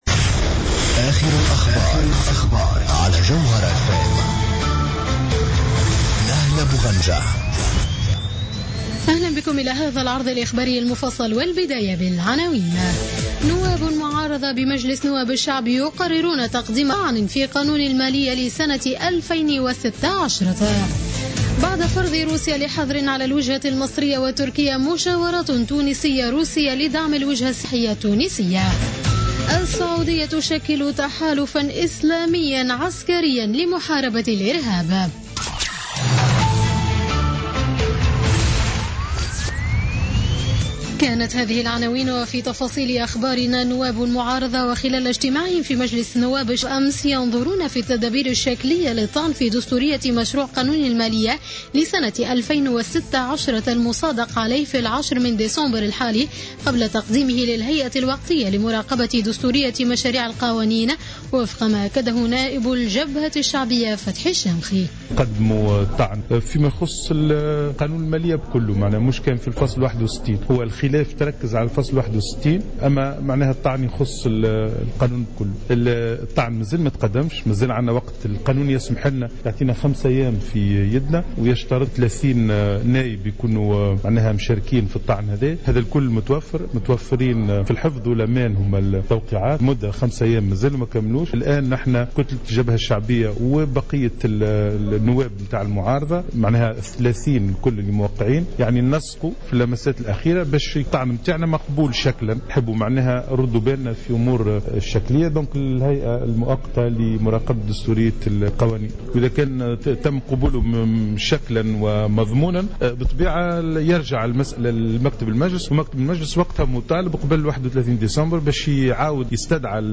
نشرة أخبار منتصف الليل ليوم الثلاثاء 15 ديسمبر 2015